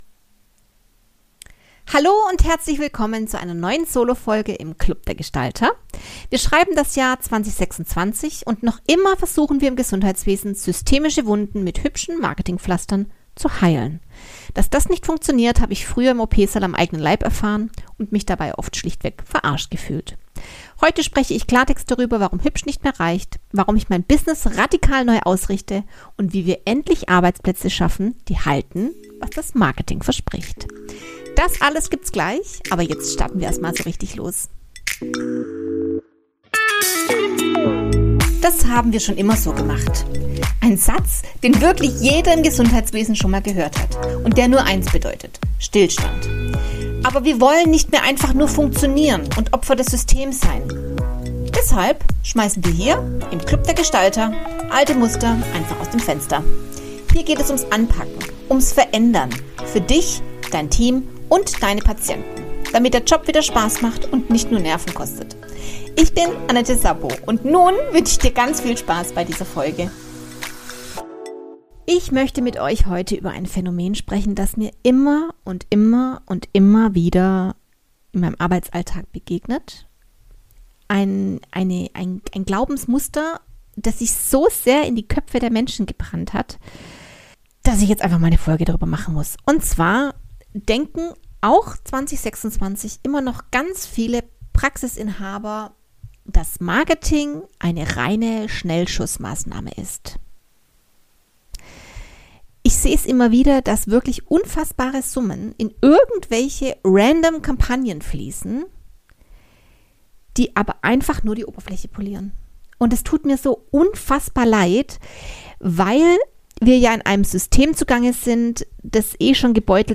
In dieser Solo-Folge wird es persönlich. Ich nehme dich mit zurück in meine Zeit im OP und erzähle dir, warum ich mich angesichts glänzender Arbeitgeber-Kampagnen oft schlichtweg verarscht gefühlt habe. Außerdem erfährst du, warum ich mein Business aktuell radikal neu ausrichte und was das für dich und deine Praxis bedeutet.